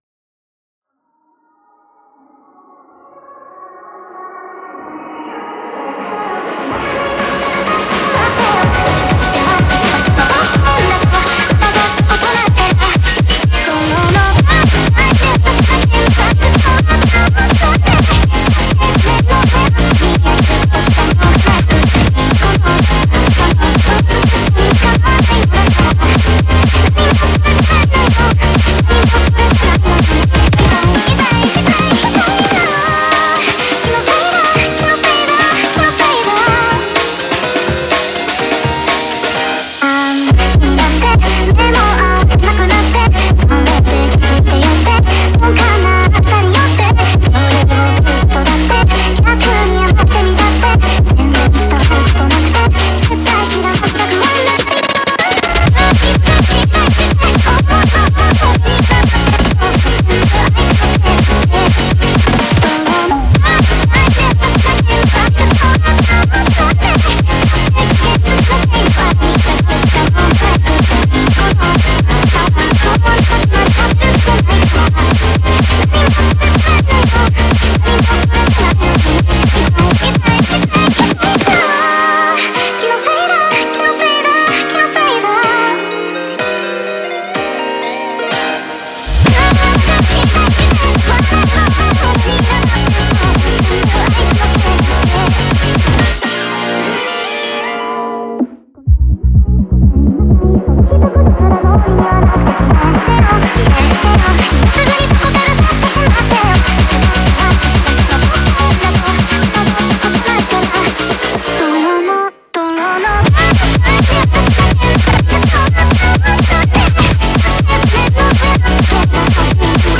JCORE